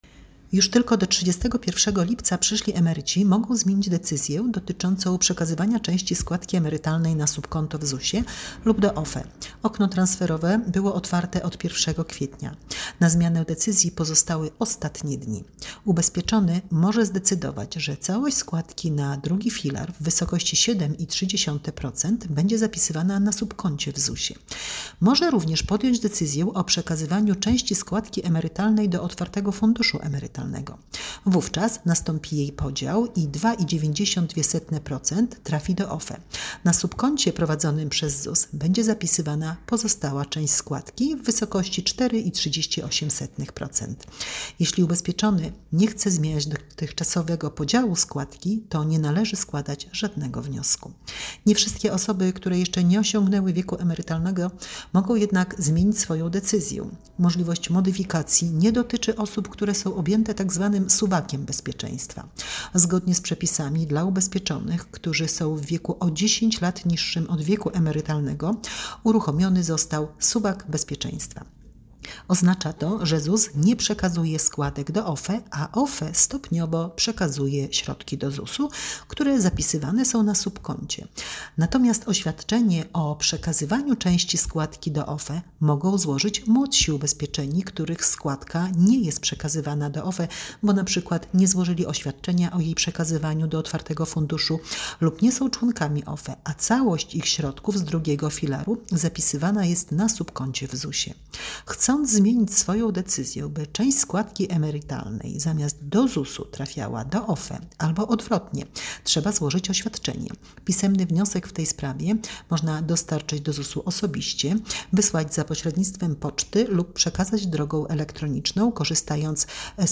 Audiodeskrypcja - okno transferowe.mp3 mp3 2,95 MB
Audiodeskrypcja_okno_transferowe.mp3